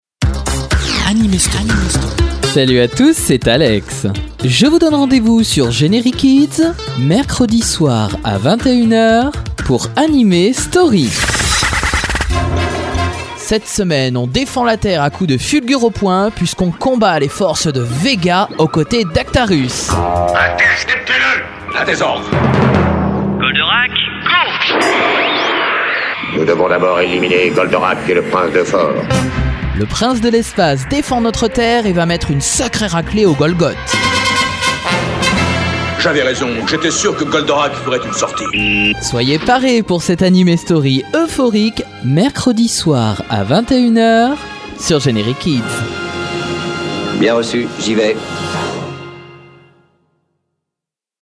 Bande Annonce Anime Story Goldorak
Voix off
- Baryton